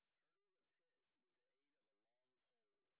sp09_white_snr30.wav